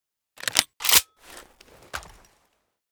load_empty.ogg.bak